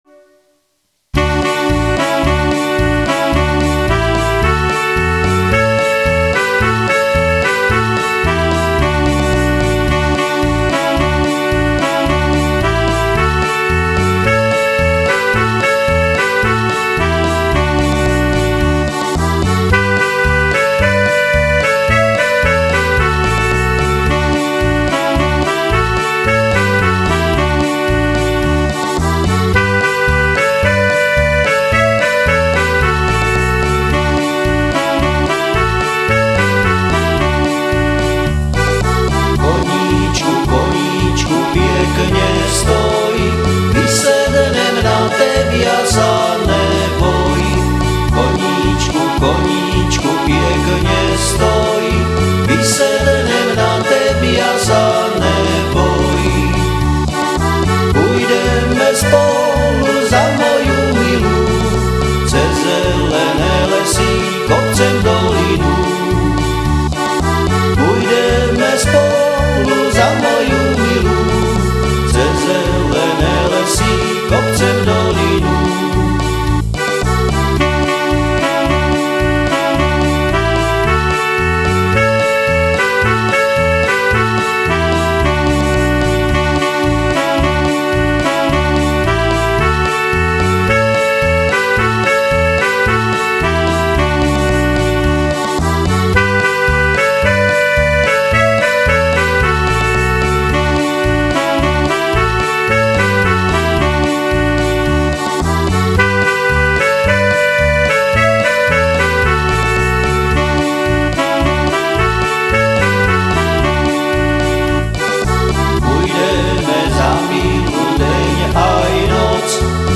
Som amatérsky muzikant, skladám piesne väčšinou v "záhoráčtine" a tu ich budem prezentovať.
A takto hrajú Koníčka" Senickí heligonkáry.